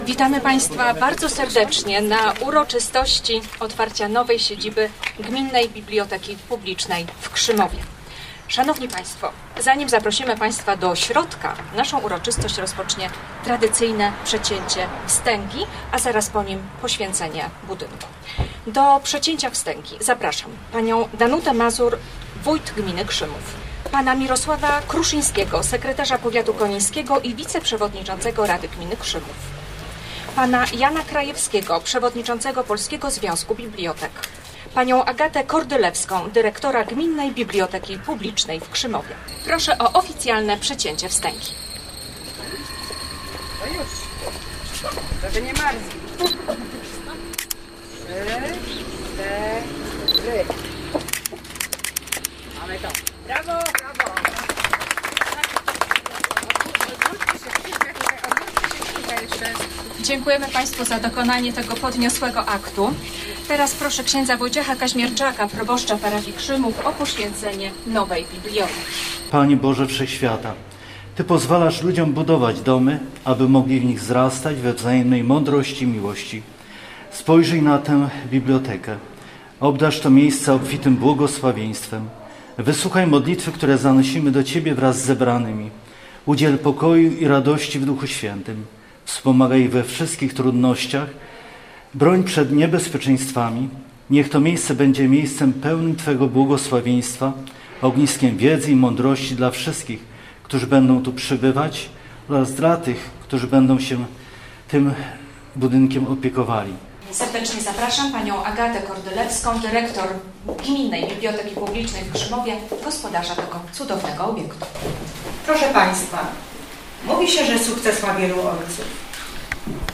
Oficjalne otwarcie tej nowoczesnej i wielofunkcyjnej placówki odbyło się dziewiątego kwietnia.
Na otwarciu z krótkim programem artystycznym wystąpiły przedszkolaki ze szkoły podstawowej w Krzymowie i uczennice szkoły podstawowej w Paprotni.